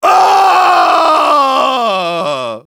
This is an audio clip from the game Team Fortress 2 .
Category:Engineer audio responses/de
Engineer_paincrticialdeath05_de.wav